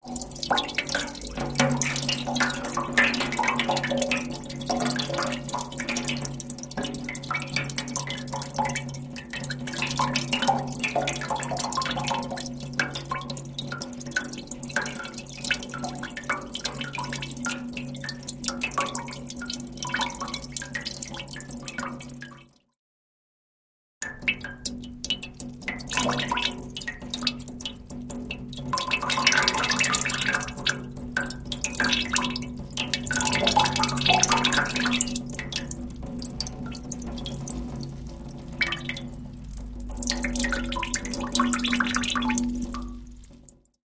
Water Trickle On A Metal Tank x2